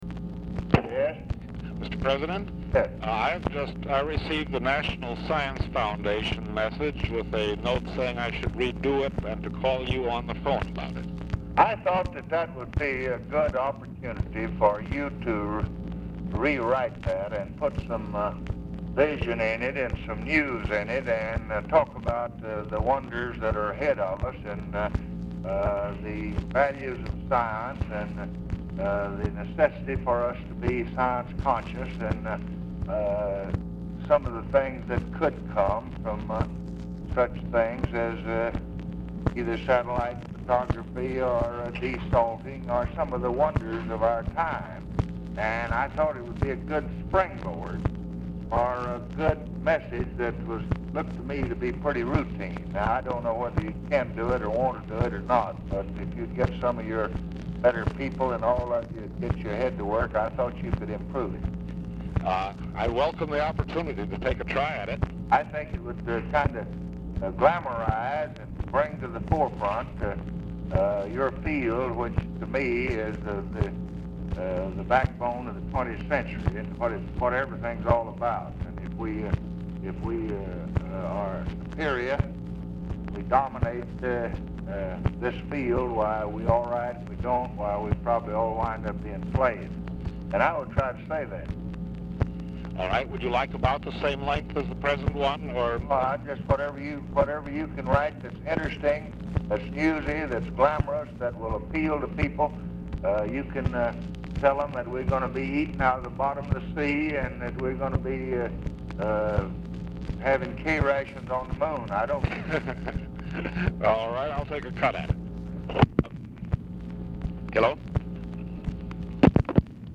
Oval Office or unknown location
Telephone conversation
Dictation belt